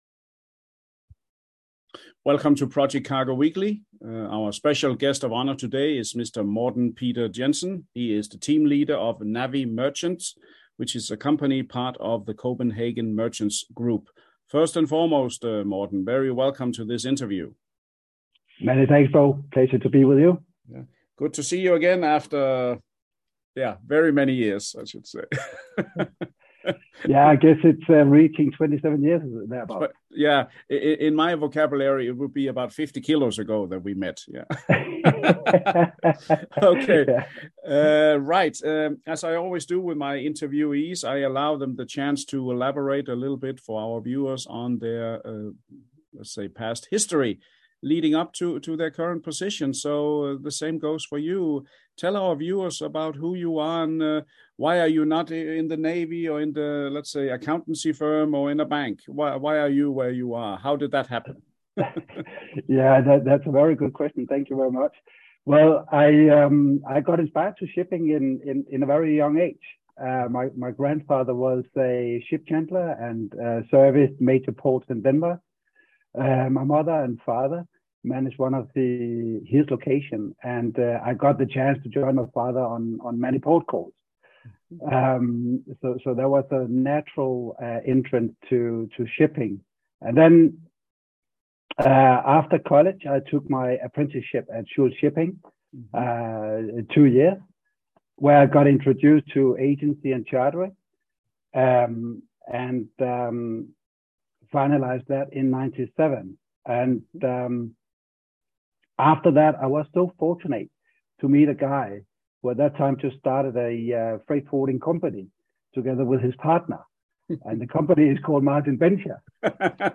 Video InterviewNavi Merchants